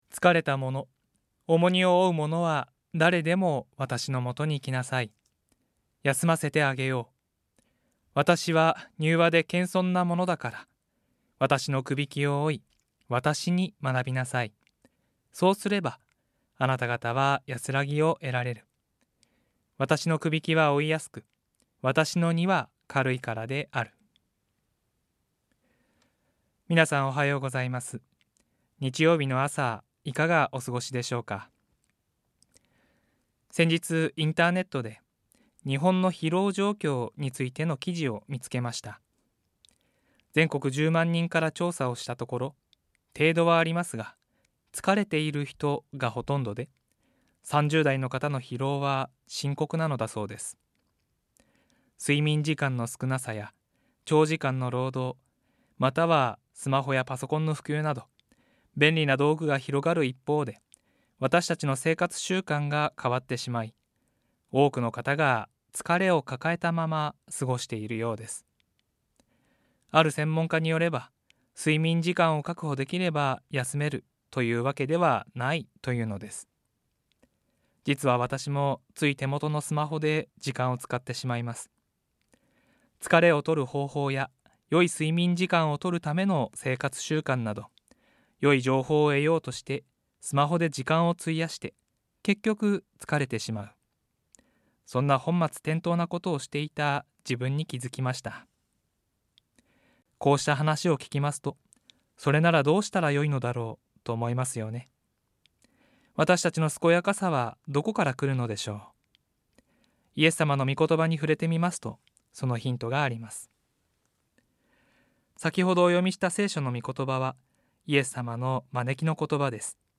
ラジオ番組